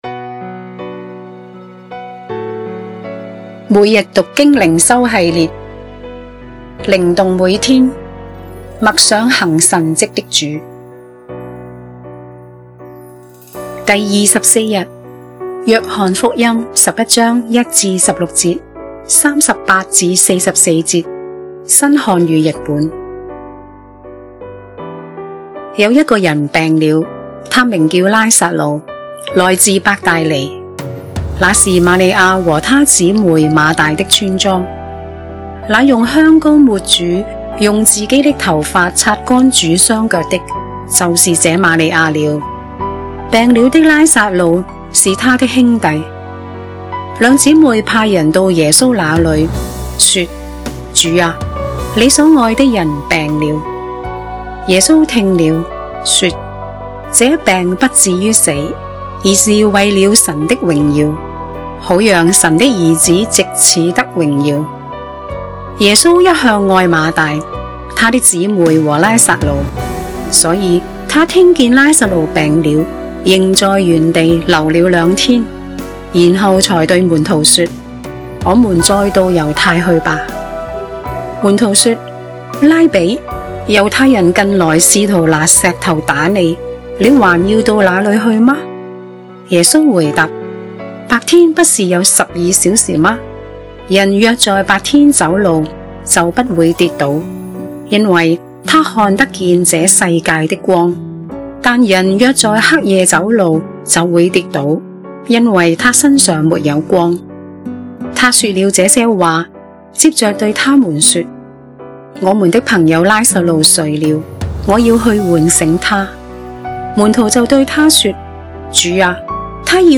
經文閱讀
音樂名稱：祢是復活和生命、哈利路亞！耶穌基督是主